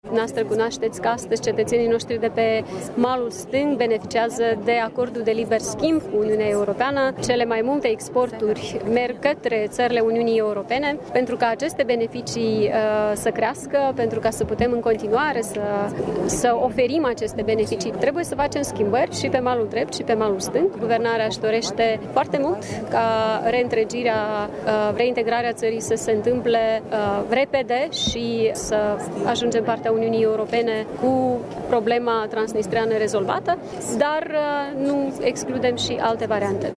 Președinta Maia Sandu a prezentat câteva explicații pe această temă, la Chișinău, la evenimentele dedicate Zilei Europei. A declarat că Guvernul dorește să convingă autoritățile separatiste de la Tiraspol să accepte o reîntregire a Republicii Moldova înainte ca țara să intre în Uniunea Europeană.